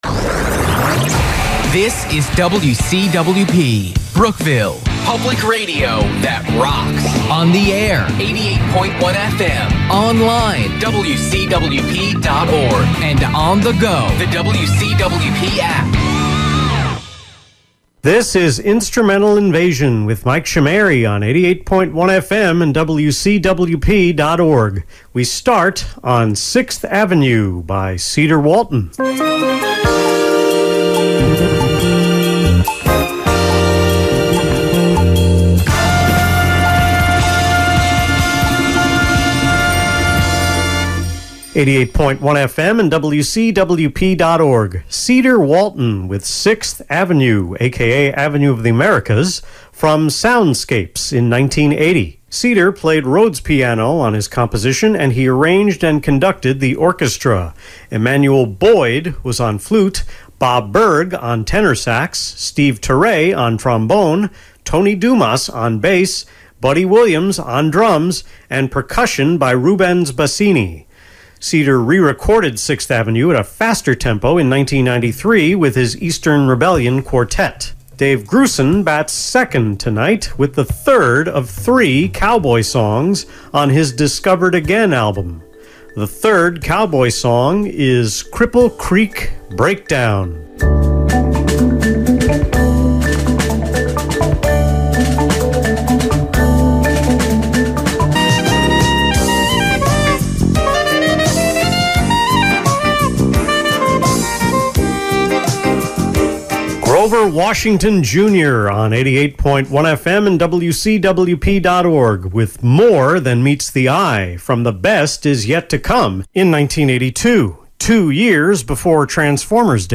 The October 6 Instrumental Invasion on WCWP was, yet again, recorded over three days: three segments on August 19, two on the 20th, one on the 21st.